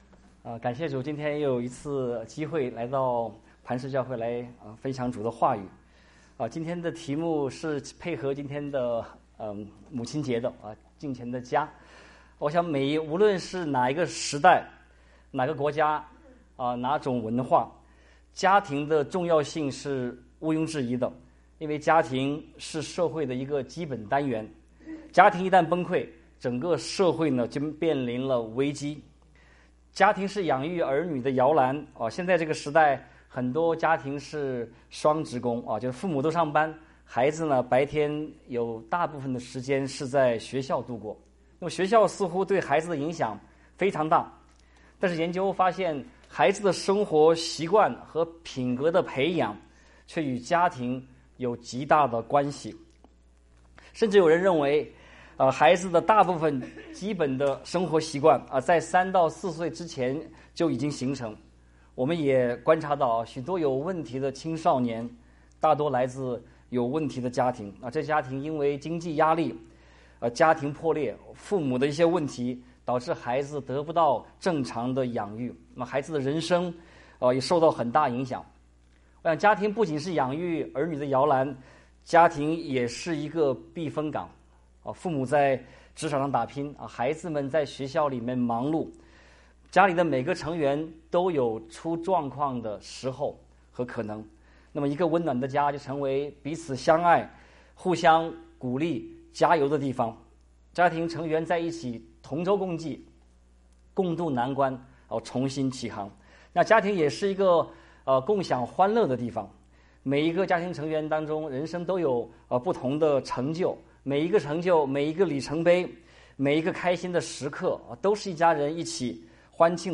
牧師